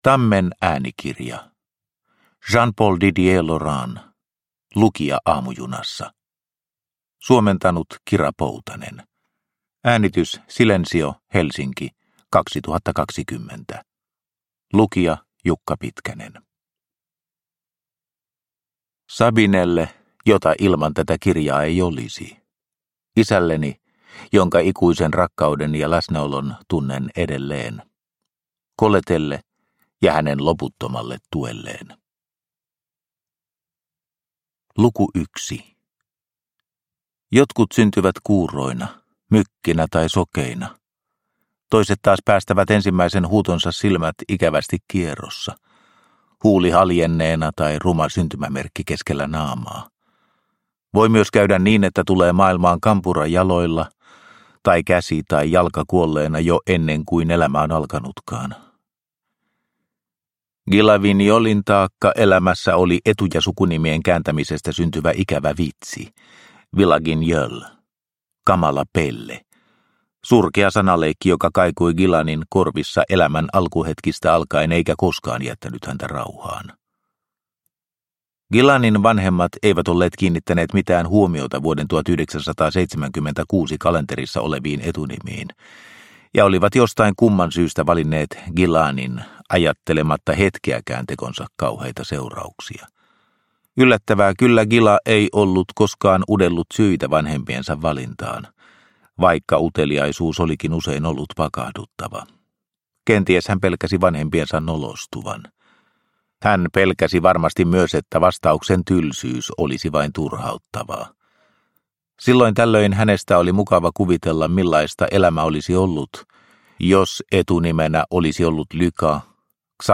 Lukija aamujunassa – Ljudbok – Laddas ner